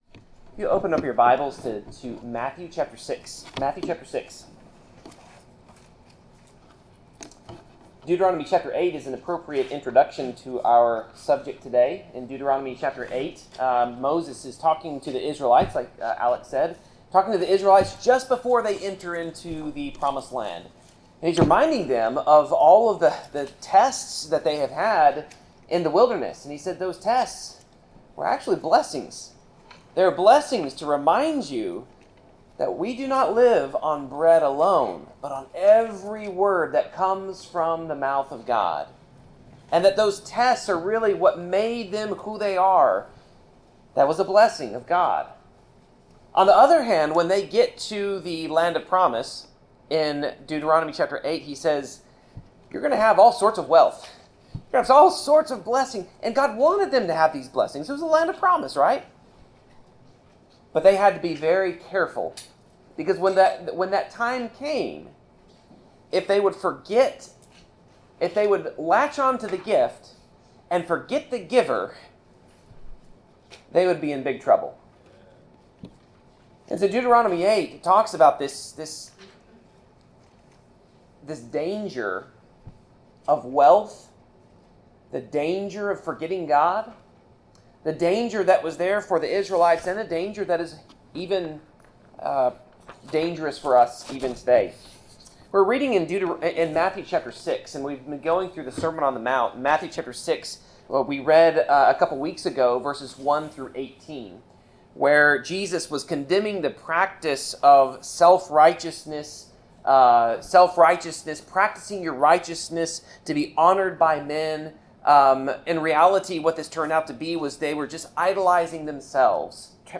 Passage: Matthew 6:19-34 Service Type: Sermon